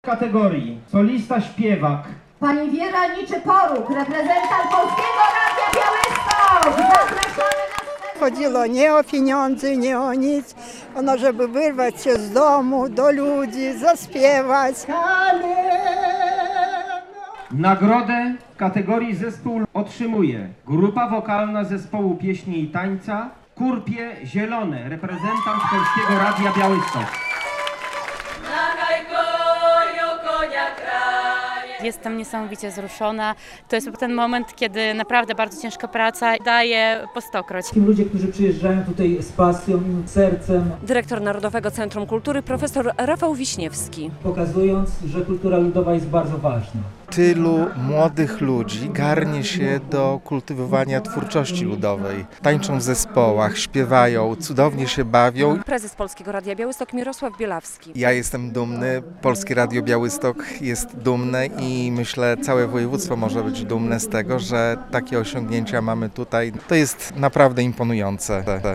W finale jury oceniało 42 artystów i twórców ludowych z całego kraju - ogłoszenie wyników odbyło się w Tokarni koło Kielc - relacja